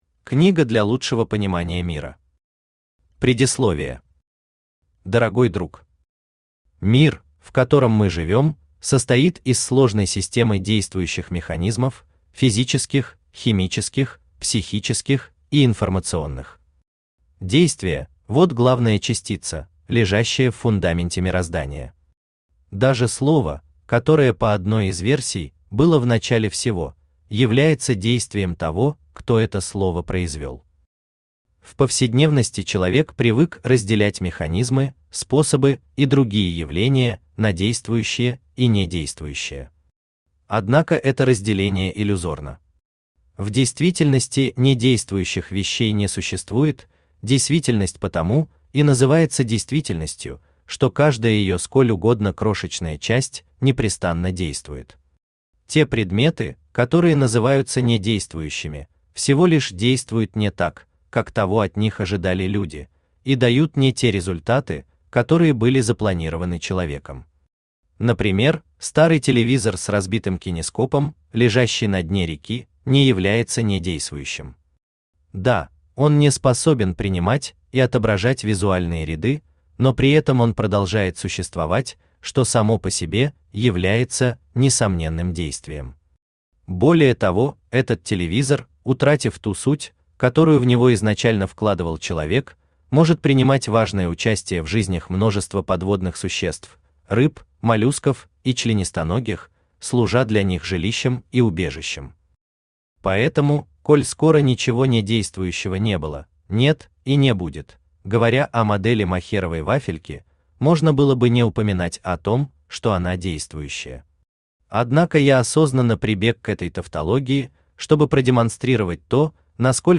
Аудиокнига Действующая Модель Мохеровой Вафельки | Библиотека аудиокниг